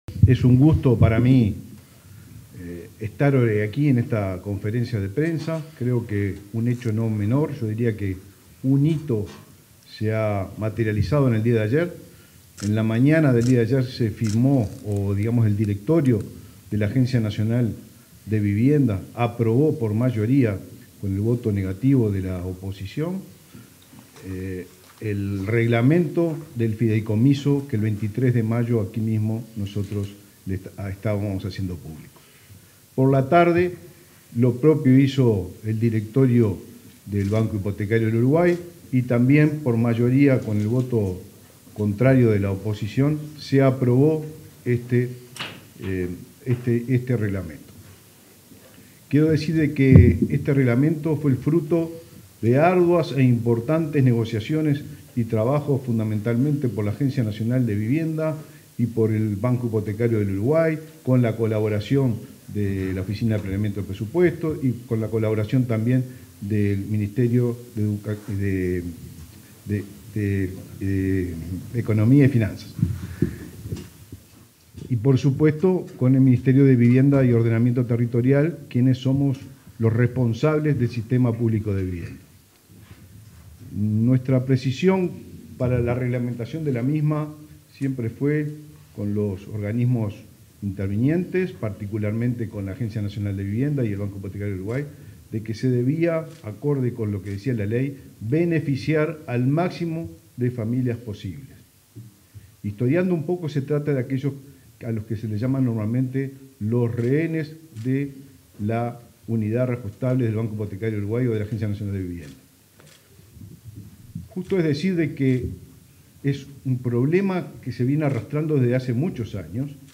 Palabras del ministro de Vivienda y Ordenamiento Territorial, Raúl Lozano
Con la presencia del ministro de Vivienda y Ordenamiento Territorial, Raúl Lozano, se realizó, este 3 de julio, una conferencia de prensa para